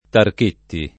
Tarchetti [ tark % tti ] cogn.